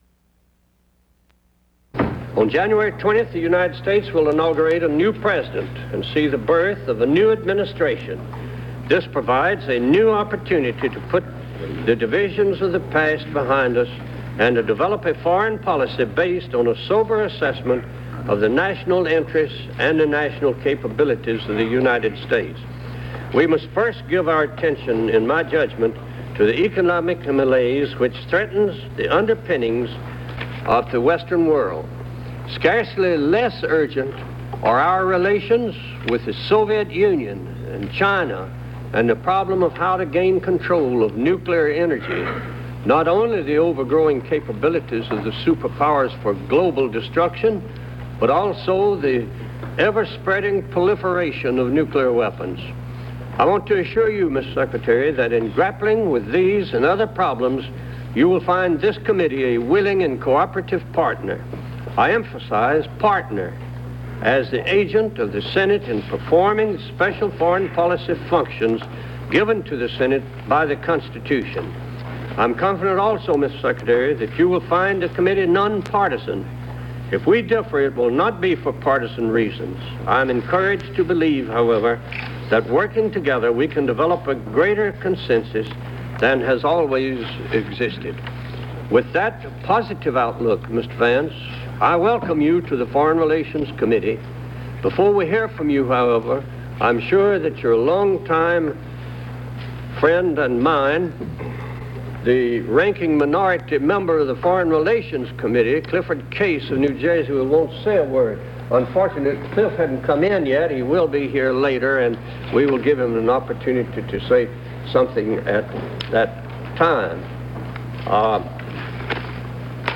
Confirmation hearing for Secretary of State designate Cyrus Vance before the Senate Foreign Relations Committee: John Sparkman, Jacob Javits, Frank Church, and Daniel Moynihan.
Subjects Vance, Cyrus R. (Cyrus Roberts), 1917-2002 Politics and government Diplomatic relations United States Material Type Sound recordings Language English Extent 00:17:20 Venue Note Broadcast 1977 January 11.